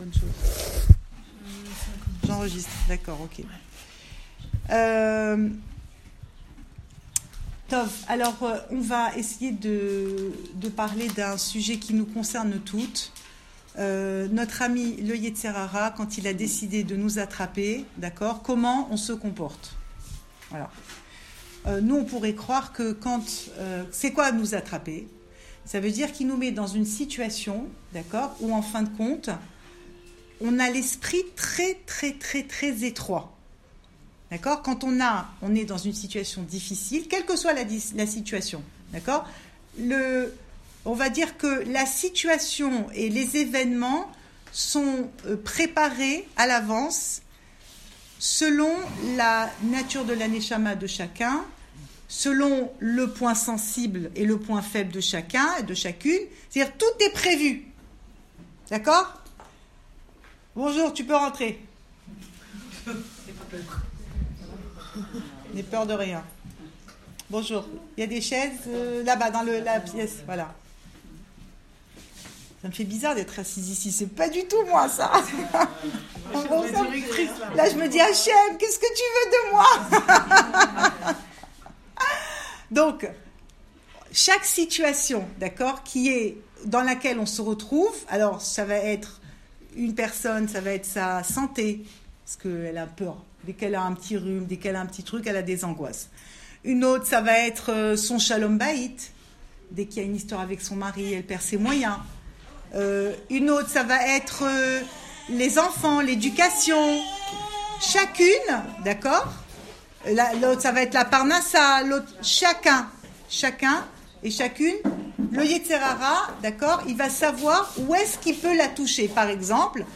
Cours audio Emouna Le coin des femmes Pensée Breslev - 6 novembre 2018 9 novembre 2018 Comment j’aborde mon épreuve ? Enregistré à Raanana